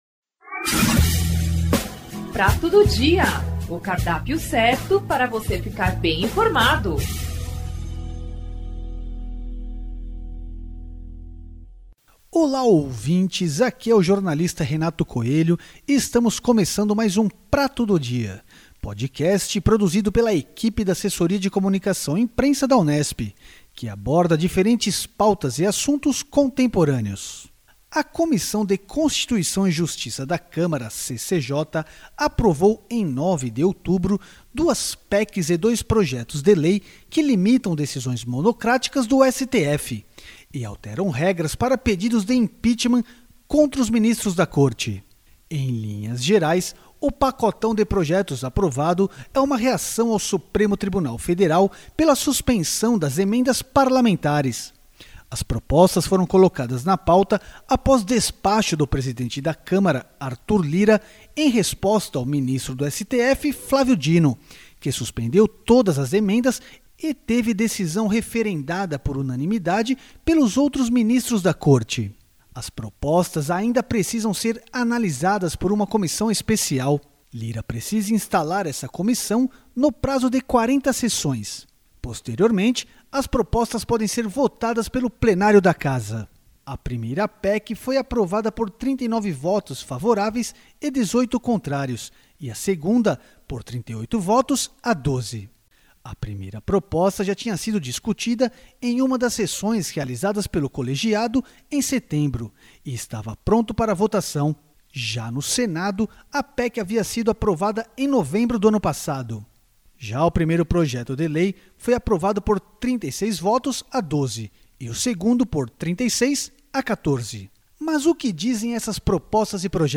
O “Prato do Dia”, Podcast da Assessoria de Comunicação e Imprensa da Reitoria da Unesp é um bate-papo e uma troca de ideias sobre temas de interesse da sociedade. De maneira informal debateremos tópicos atuais, sempre na perspectiva de termos o contra-ponto, o diferencial.